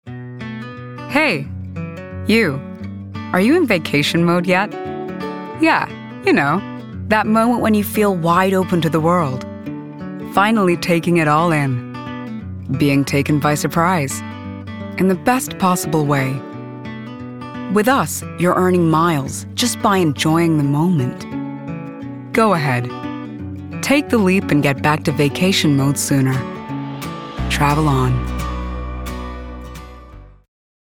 Female
Husky
Warm
CITIBANK COMMERCIAL (US ACCENT)